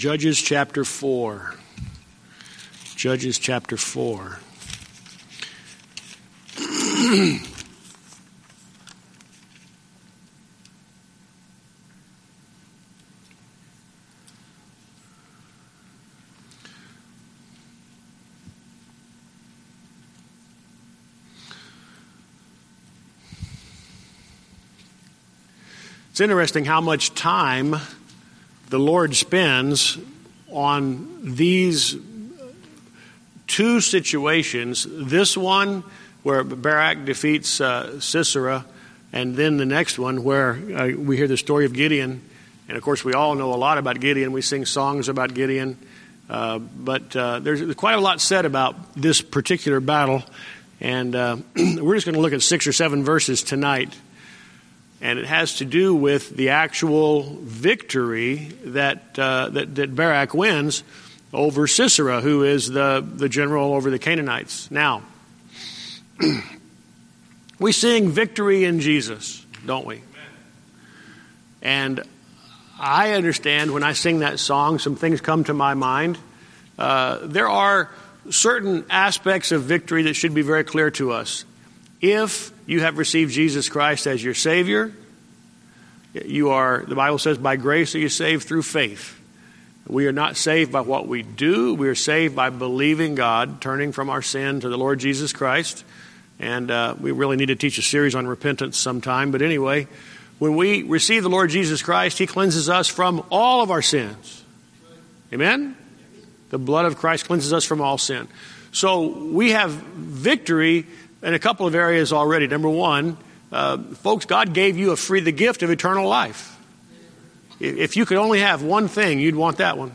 Sermon Recordings